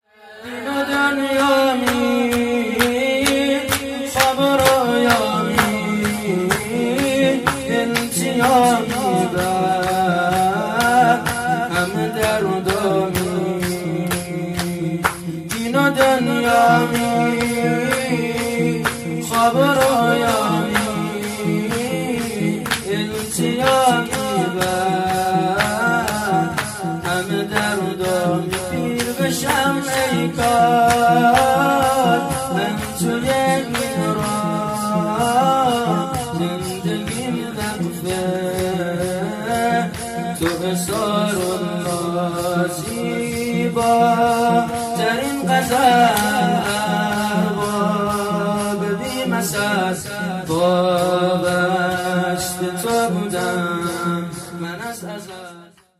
شور دین و دنیامی